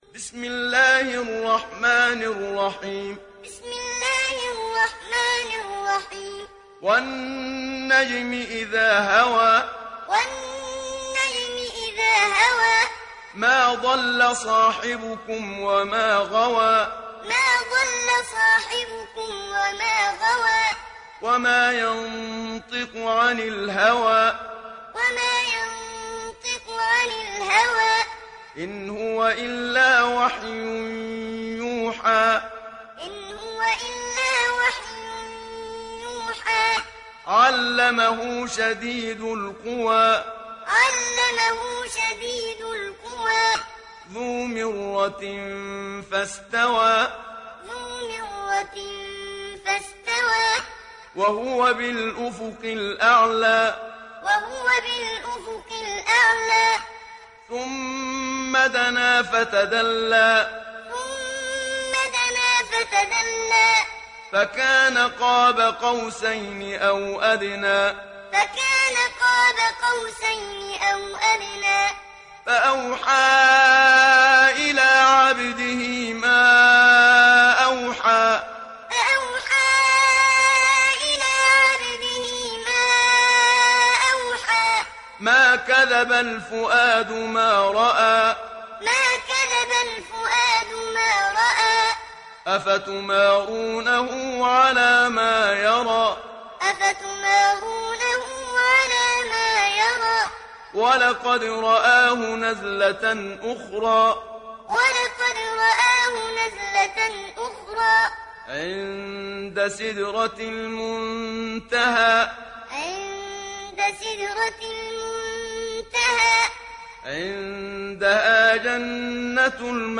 সূরা আন-নাজম ডাউনলোড mp3 Muhammad Siddiq Minshawi Muallim উপন্যাস Hafs থেকে Asim, ডাউনলোড করুন এবং কুরআন শুনুন mp3 সম্পূর্ণ সরাসরি লিঙ্ক
Muallim